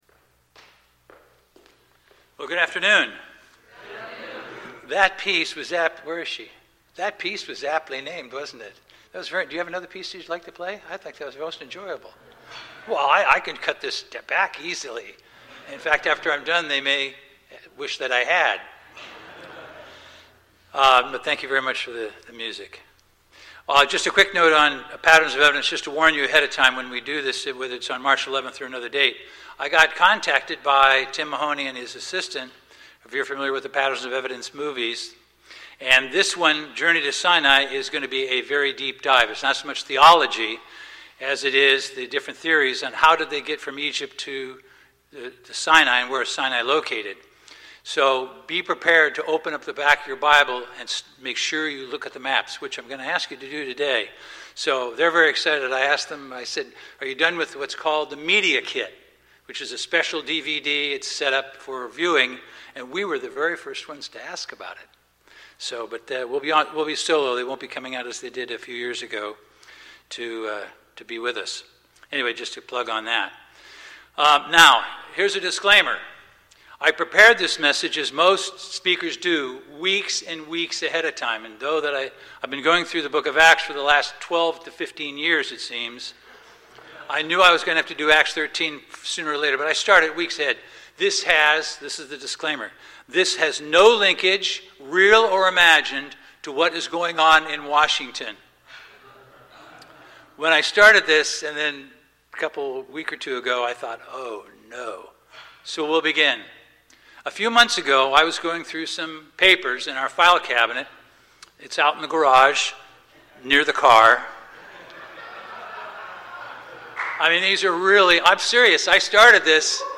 Sermons
Given in Bakersfield, CA Los Angeles, CA